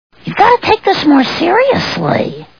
The Simpsons [Marge] Cartoon TV Show Sound Bites